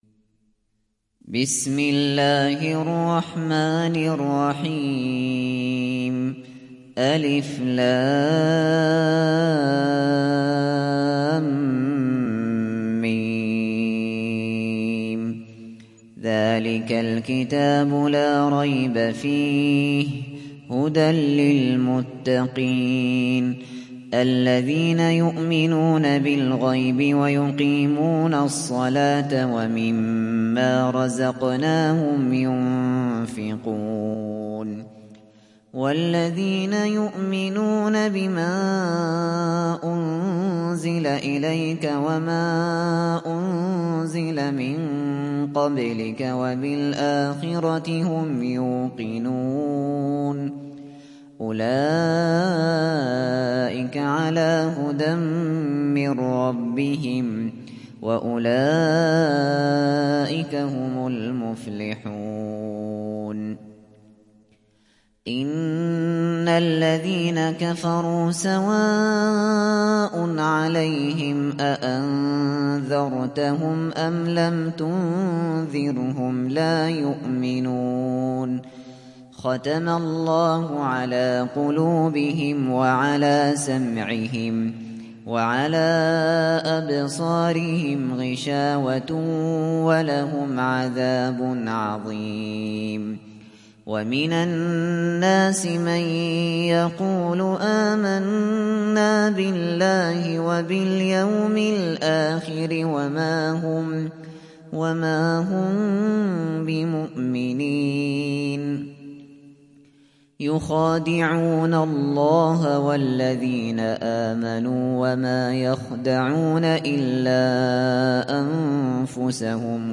تحميل سورة البقرة mp3 بصوت أبو بكر الشاطري برواية حفص عن عاصم, تحميل استماع القرآن الكريم على الجوال mp3 كاملا بروابط مباشرة وسريعة